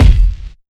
KICKSTOMP.wav